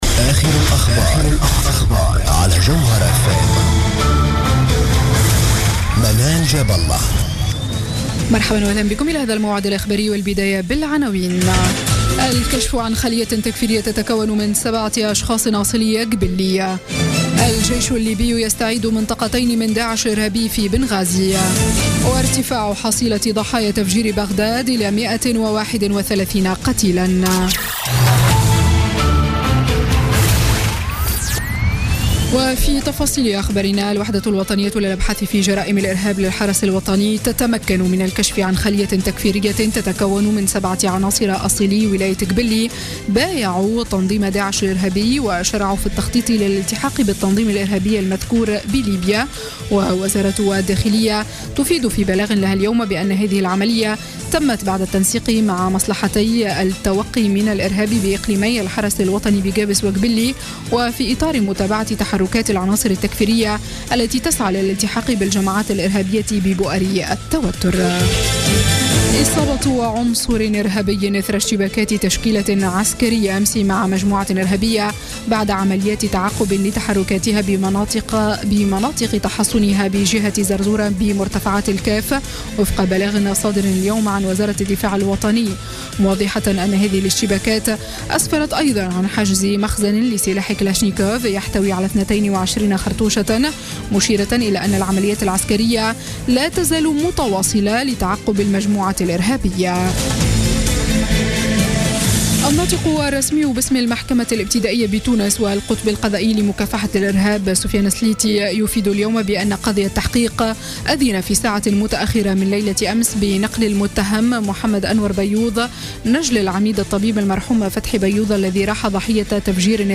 نشرة أخبار الخامسة مساء ليوم الأحد 3 جويلية 2016